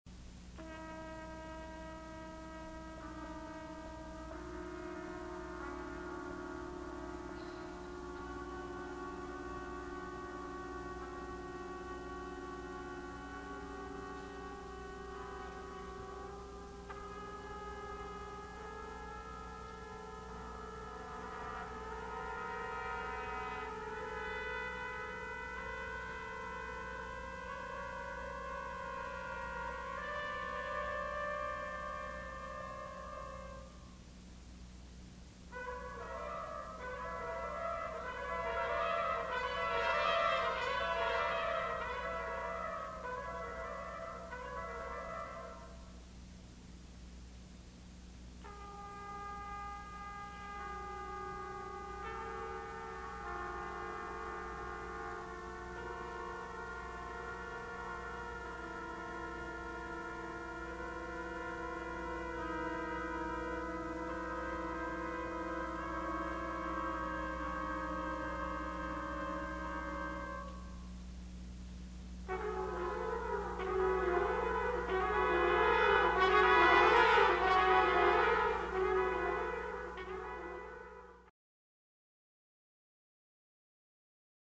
for four trumpets